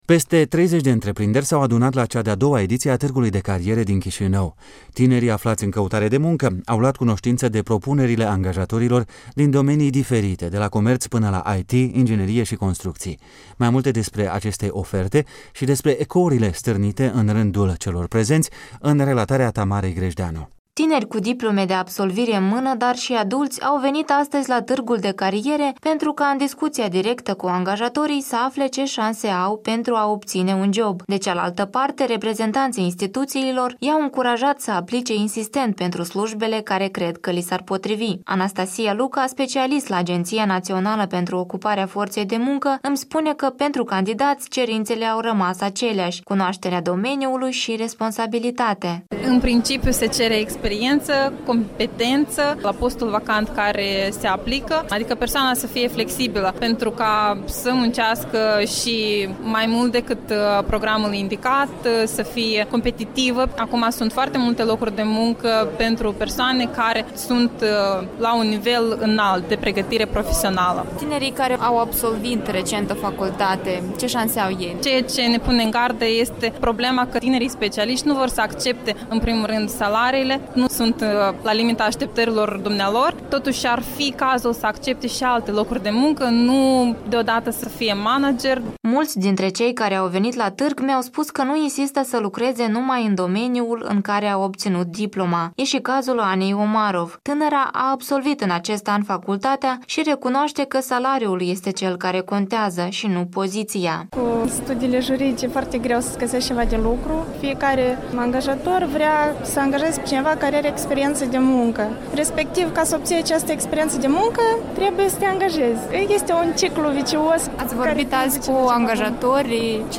Tineri în căutare de slujbe - un reportaj de la Tîrgul de Cariere de la Chișinău